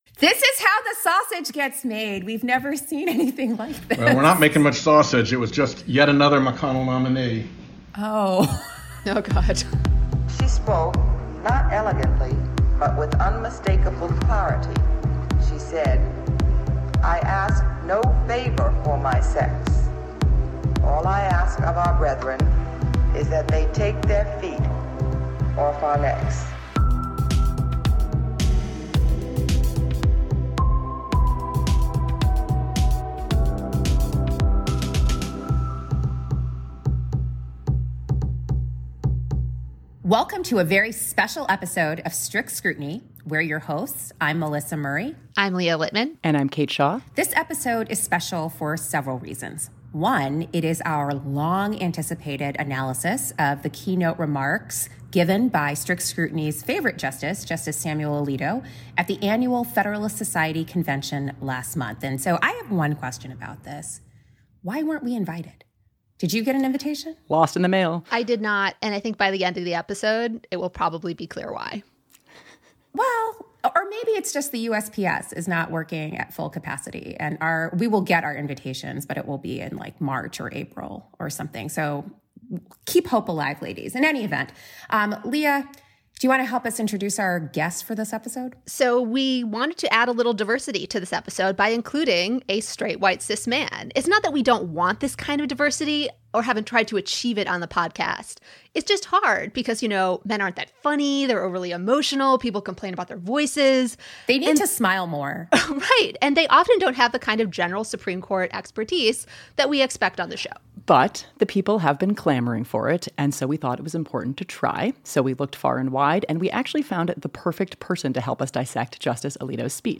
It’s the episode you’ve been waiting for – our breakdown of Justice Alito’s keynote speech at the Federalist Society convention. And as our guest, we have the Senator living rent free in Justice Alito’s head – Senator Sheldon Whitehouse of Rhode Island, who gets his own Strict Scrutiny nickname in this episode!